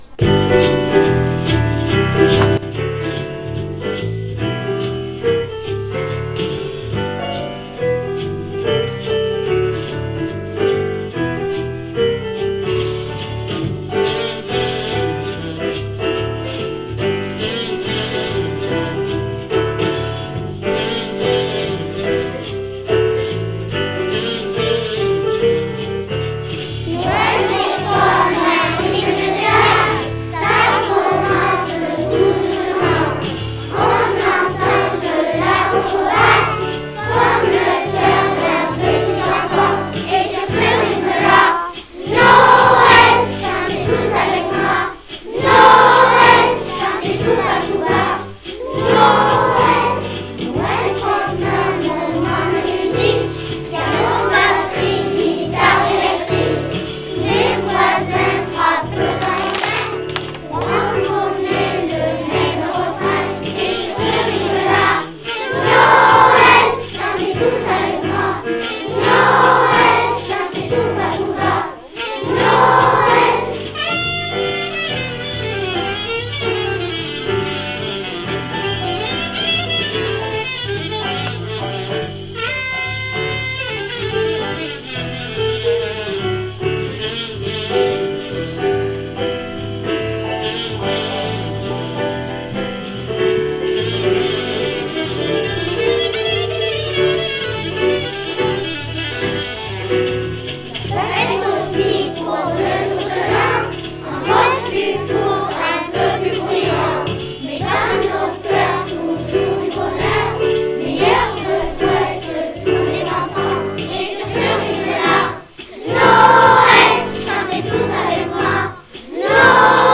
Ecole Primaire Publique de Puygouzon de Puygouzon chorale de Noël